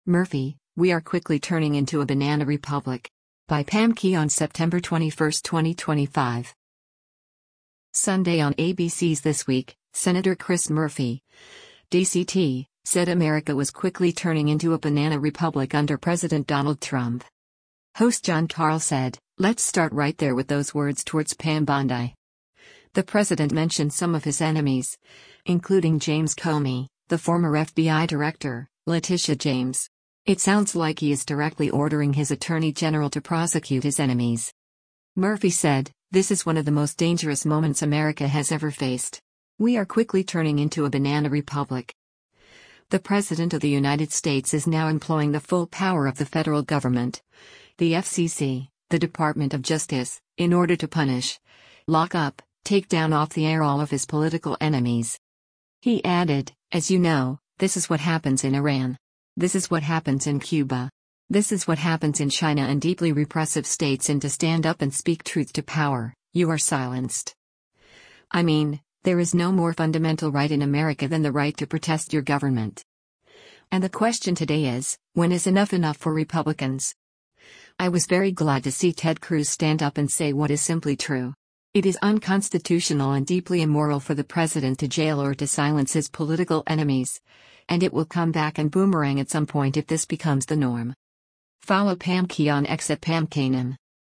Sunday on ABC’s “This Week,” Sen. Chris Murphy (D-CT) said America was “quickly turning into a banana republic” under President Donald Trump.